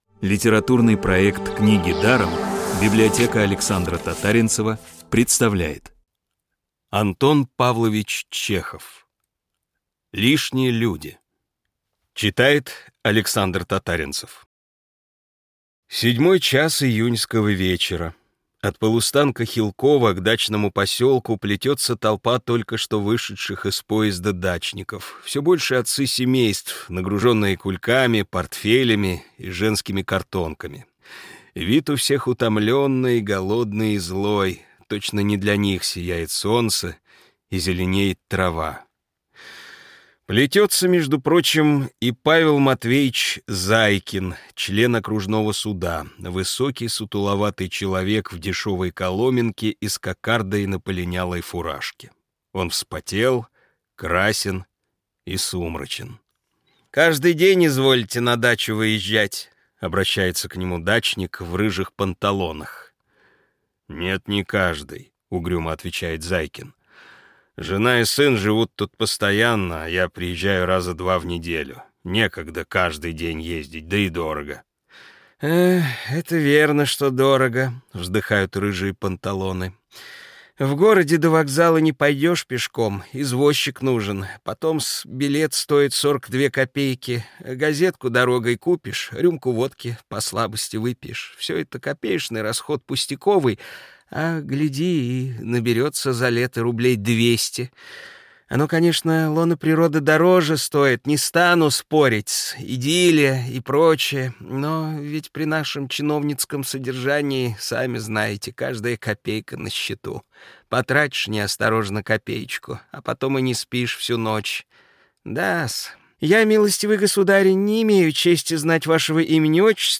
Аудиокниги онлайн – слушайте «Лишних людей» в профессиональной озвучке и с качественным звуком.